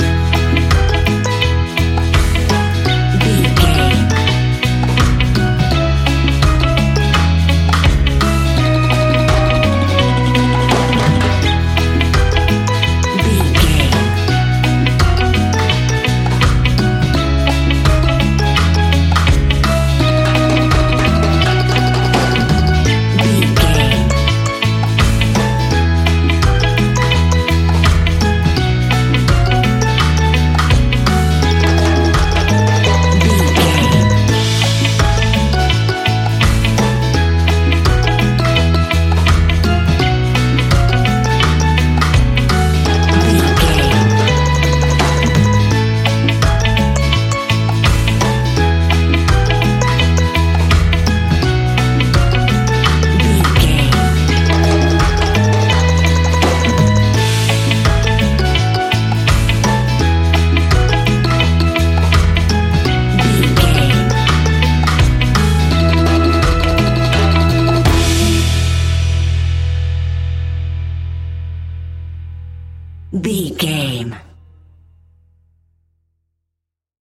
That perfect carribean calypso sound!
Ionian/Major
Slow
steelpan
happy
drums
bass
brass
guitar